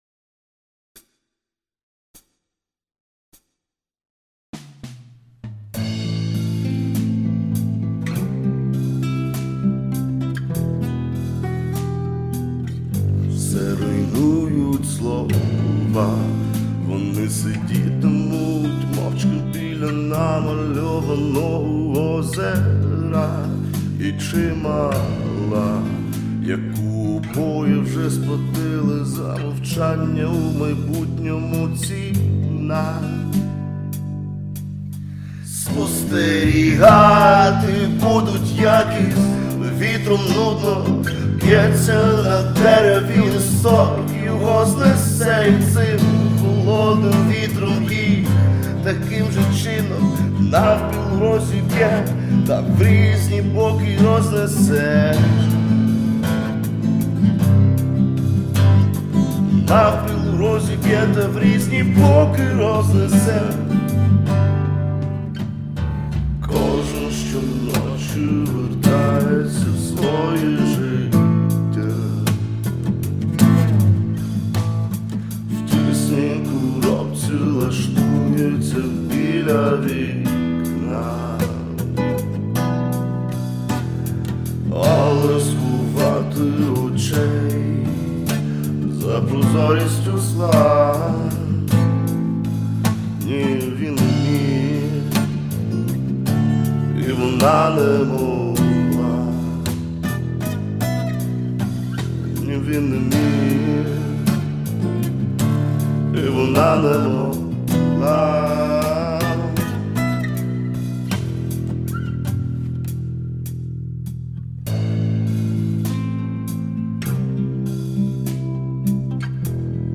ТИП: Пісня
СТИЛЬОВІ ЖАНРИ: Ліричний
ВИД ТВОРУ: Авторська пісня
Ааааа, це драйвово.
Дуже красиво і емоційно